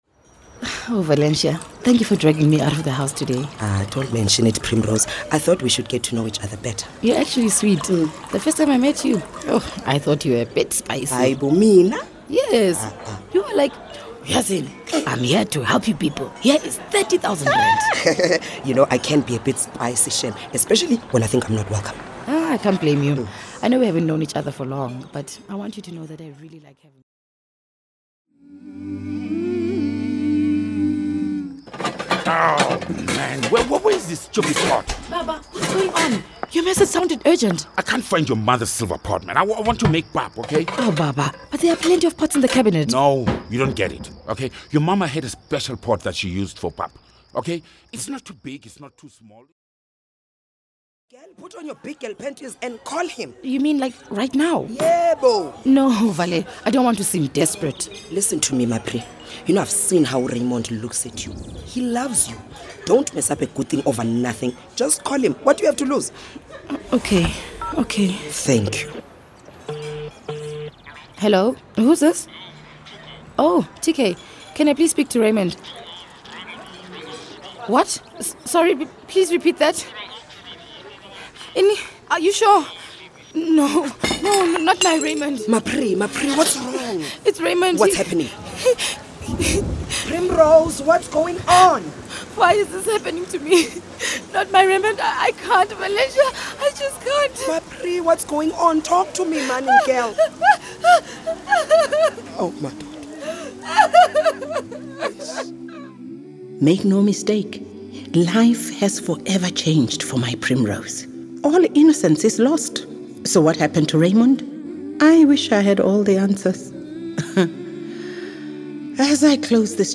(Radio Drama Series)